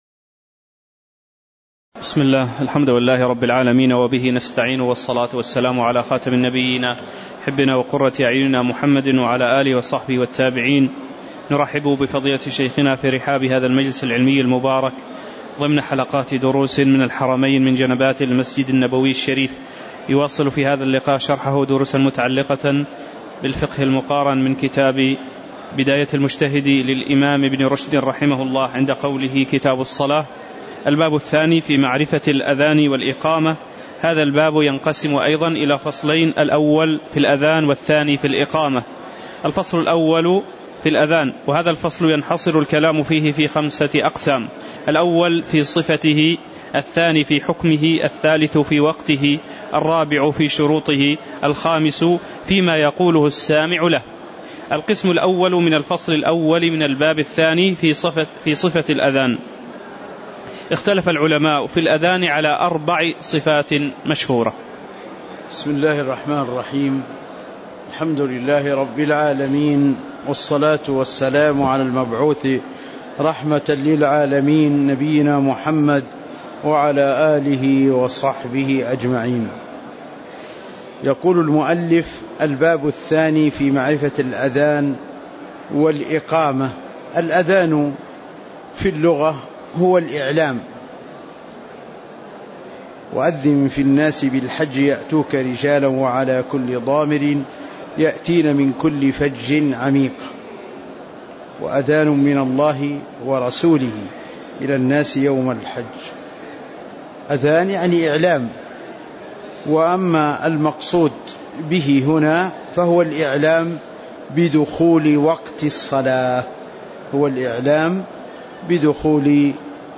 تاريخ النشر ٢٨ صفر ١٤٤١ هـ المكان: المسجد النبوي الشيخ